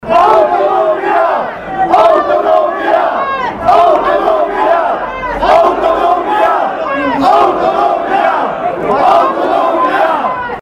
Circa o mie de persoane au participat marţi dupa-amiază la manifestările organizate la Sfântu Gheorghe cu prilejul Zilei libertăţii secuieşti, în cadrul cărora au cerut şi respectarea drepturilor minorităţii maghiare.
Pe traseu, participanţii au scandat “Autonomie”, “Ţinutul secuiesc nu este România”şi “Piară Trianonul”.
autonomie-slogan-1.mp3